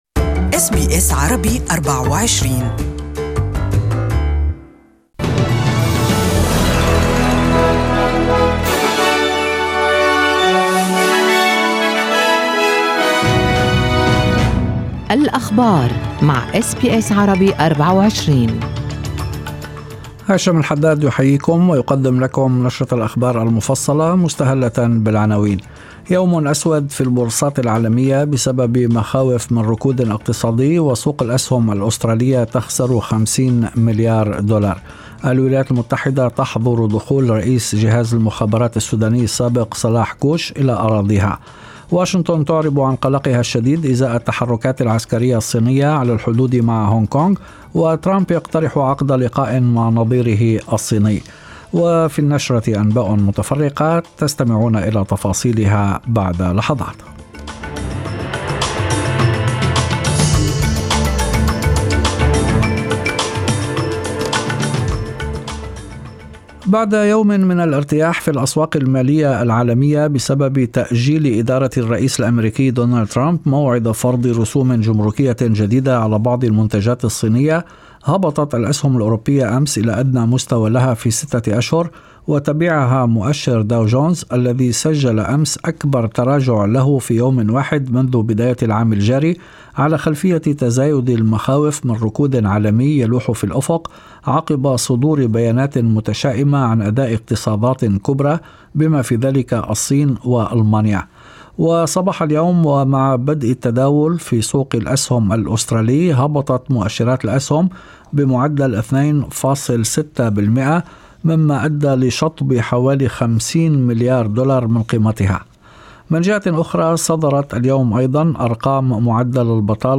Evening News: Opposition demands increase in Newstart Allowance